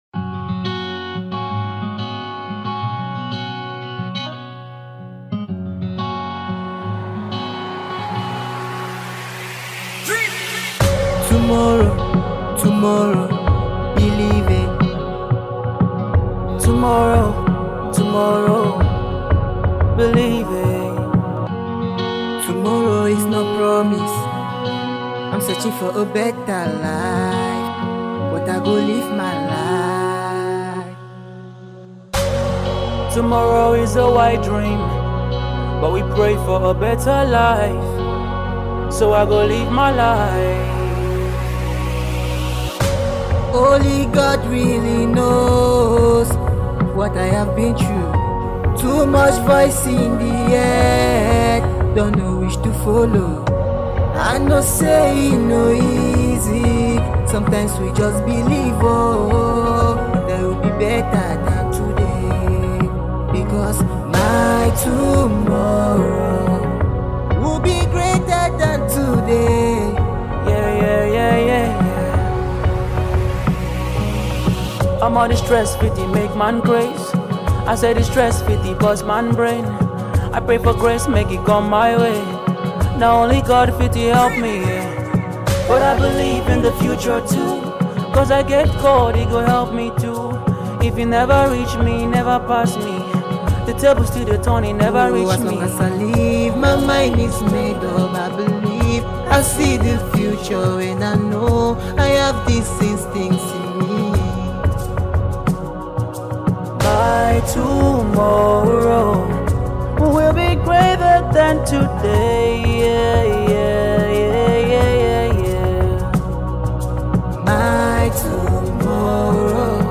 Afro singer